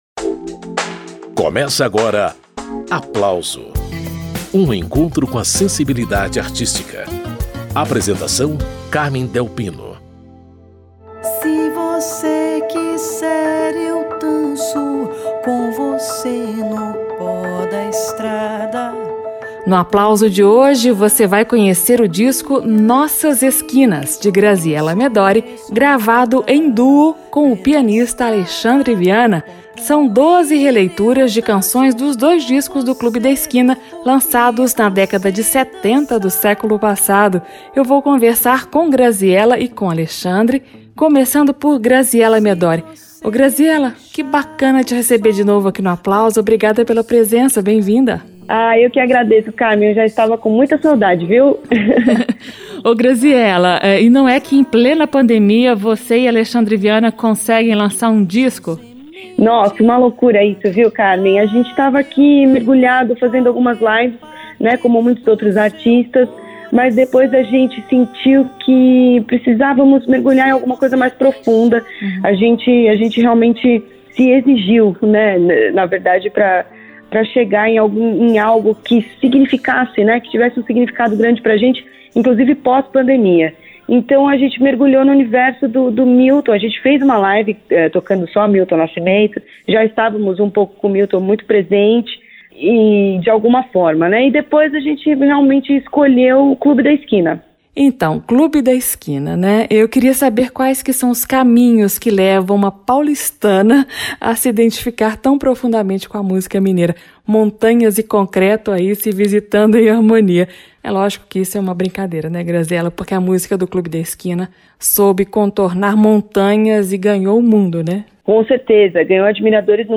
Piano e voz para alcançar os caminhos de Minas.